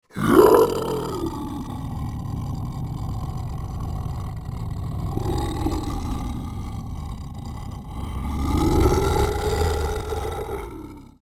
Scary Monster Growl Roar 13 Sound Button - Free Download & Play
Animal Sounds Soundboard102 views